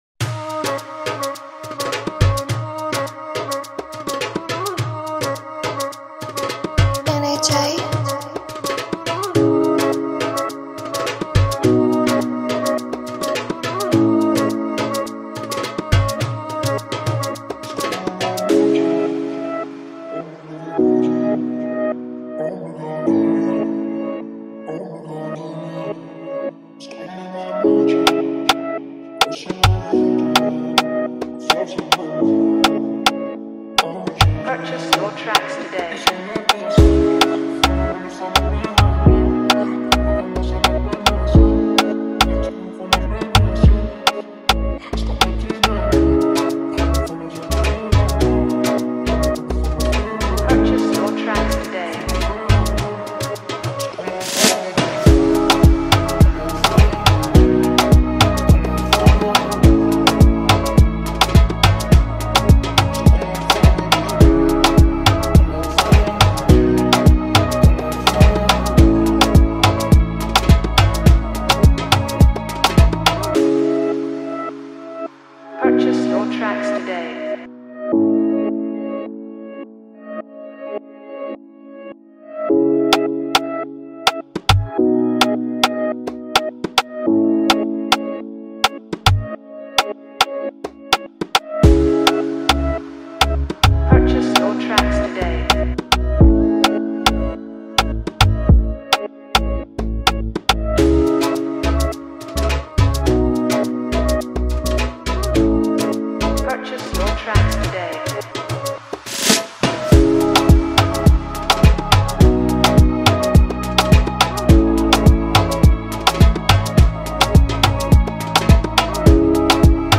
(Turkish Oriental Dancehall Balkan Instrumental 2025)